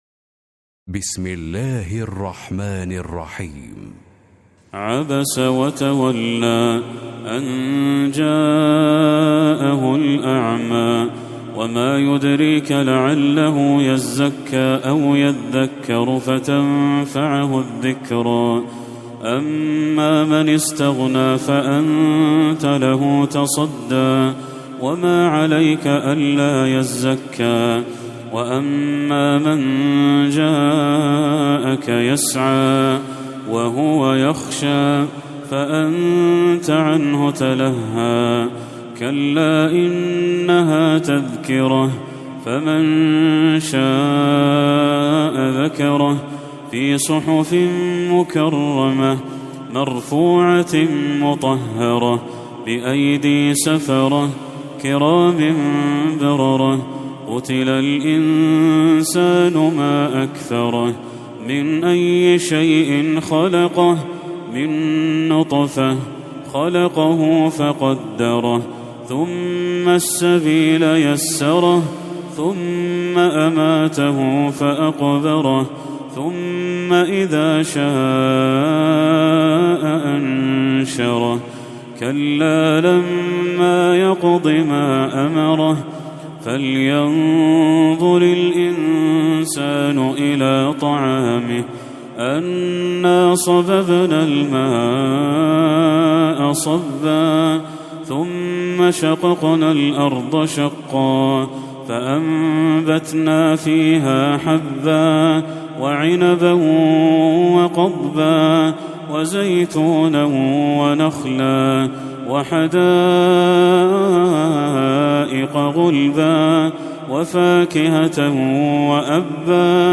سورة عبس Surat Abasa > المصحف المرتل